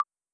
GenericButton5.wav